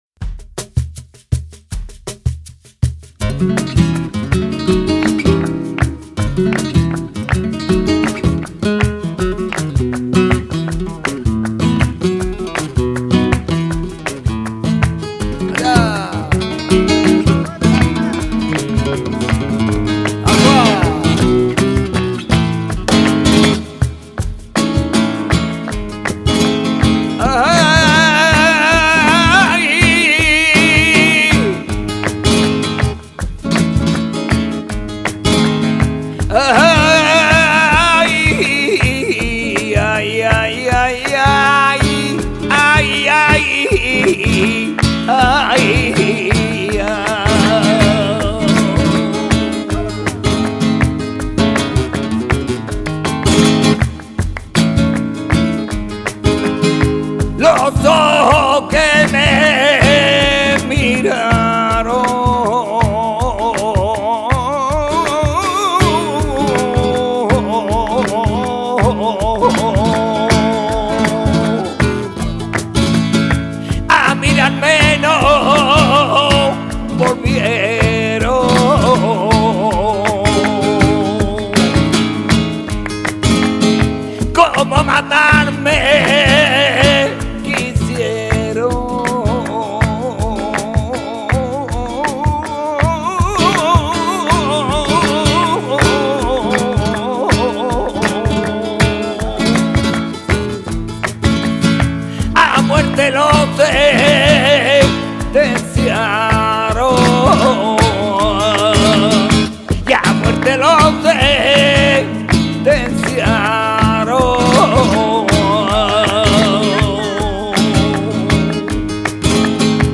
guitare
tangos extremeños